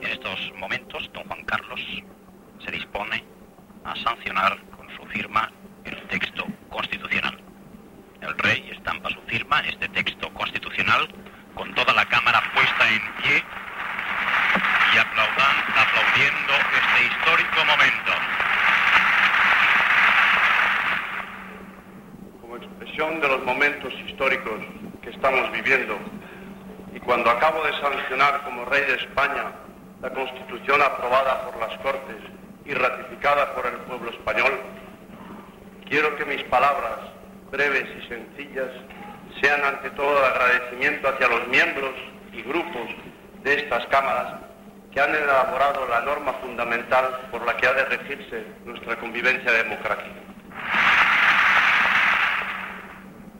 Transmissió del moment en el qual el Rei d'Espanya Juan Carlos I sanciona el text de la Constitució de 1978, signant-la en una sessió conjunta del Congrés dels Diputats i el Senat, celebrada en el Palacio de las Cortes de Madrid
Informatiu